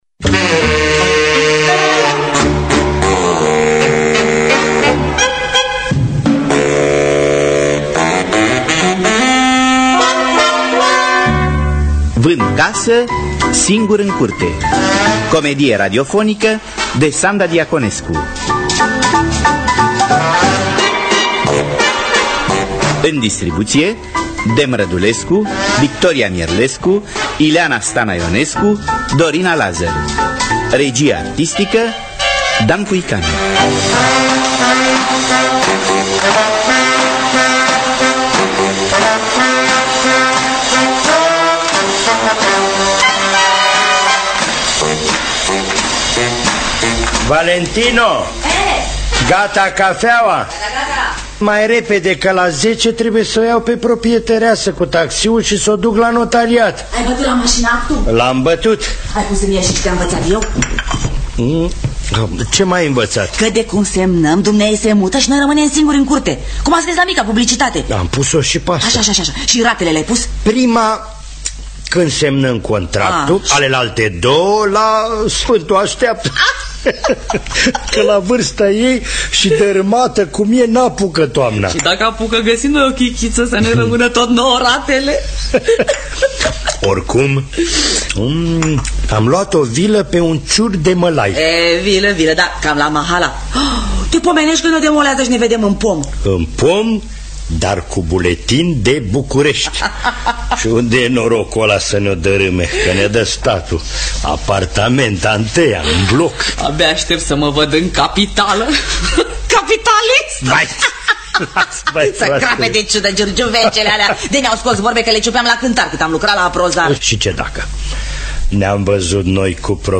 Vând casă singur în curte de Sanda Diaconescu – Teatru Radiofonic Online